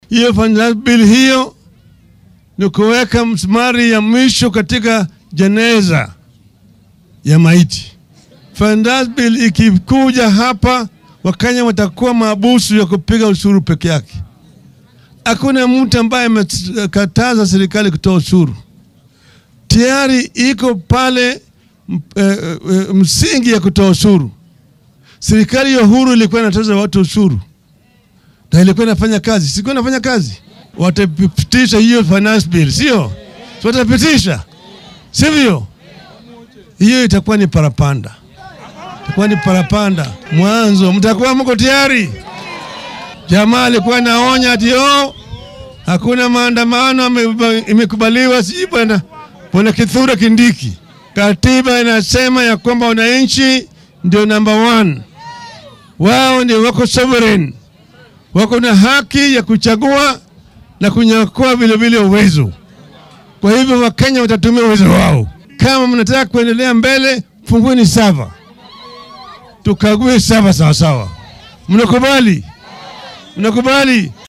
Mr. Odinga ayaa xilli uu booqday ganacsatada suuqa Toi ee ismaamulka Nairobi oo Axaddi hore uu dab qabsaday waxaa uu dowladda ku dhaliilay qorshaheeda maaliyadeed.